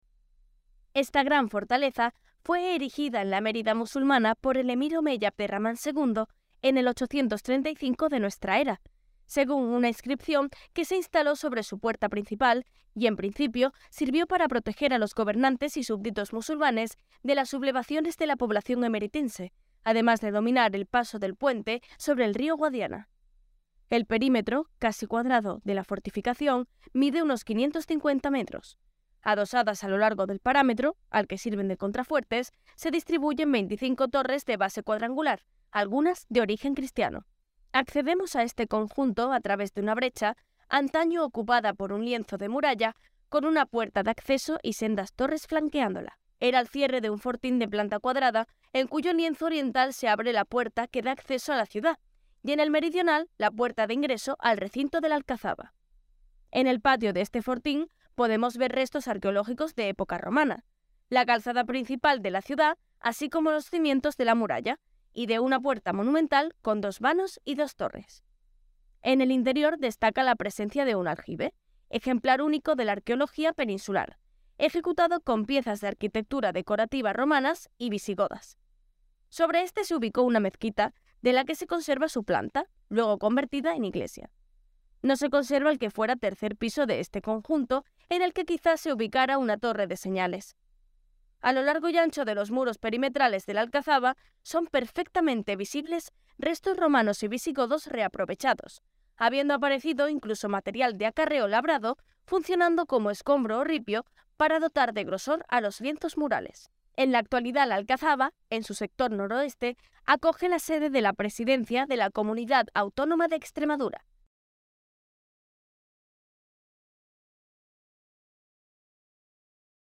Audioguía Alcazaba Árabe